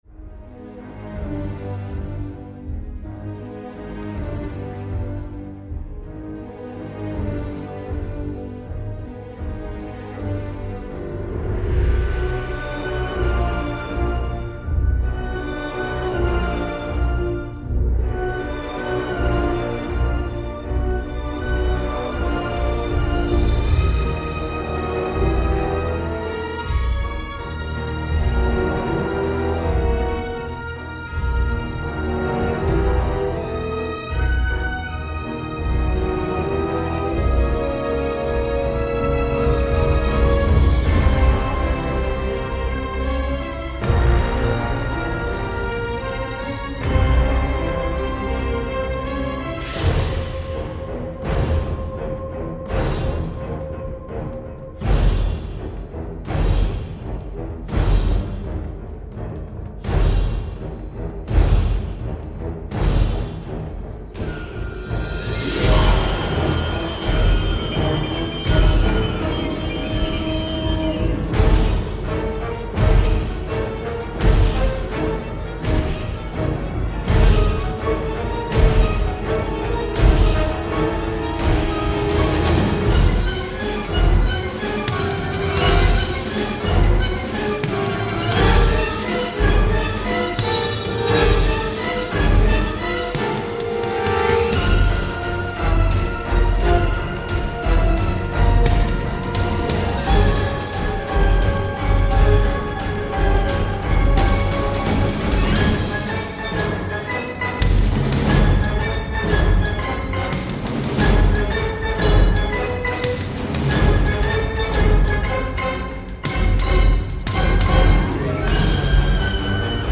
Naviguant entre mystère et déflagrations d’action dramatique
sait gérer un grand ensemble orchestral.